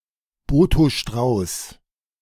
Botho Strauss (German: [ˈboːtoː ˈʃtʁaʊs]
De-Botho_Strauß.ogg.mp3